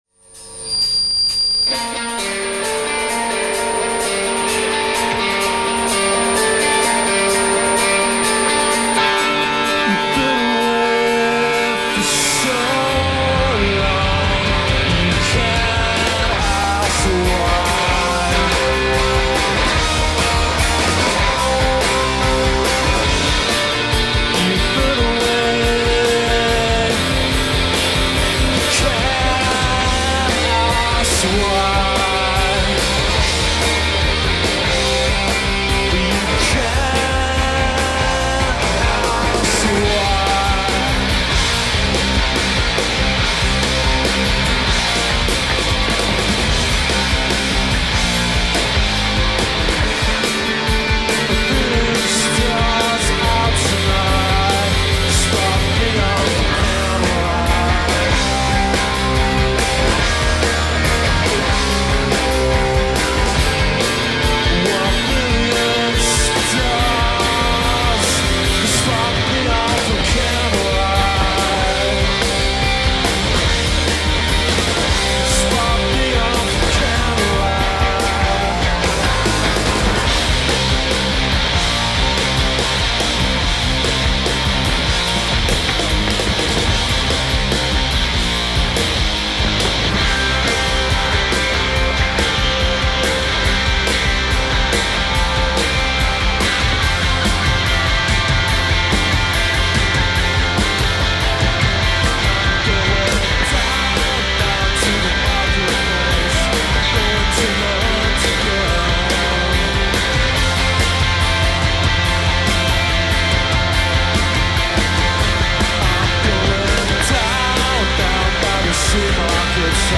The Astoria, London - Band soundboard.
recorded at The Astoria in London on October 17, 1993.